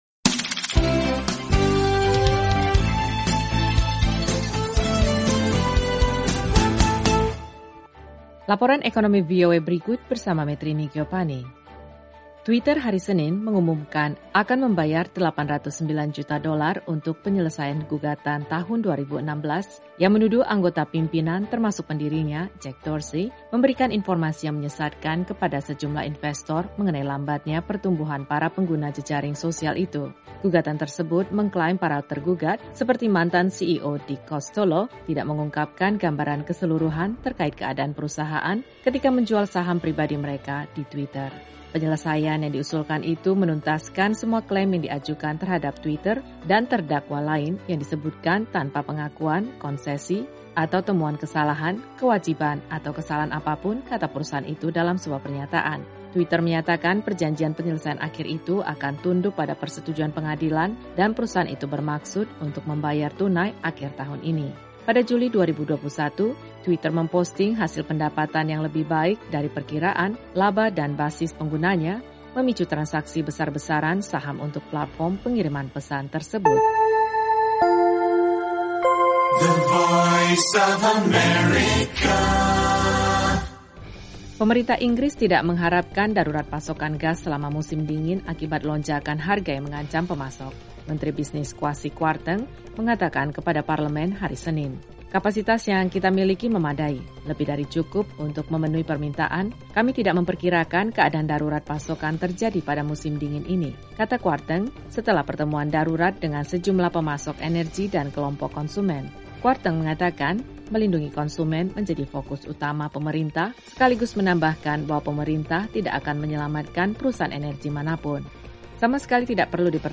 Laporan Ekonomi VOA hari ini terkait Twitter yang akan membayar $809 Juta untuk penyelesaian gugatan. Simak juga laporan mengenai pemerintah Inggris yang mengantisipasi darurat pasokan gas musim dingin.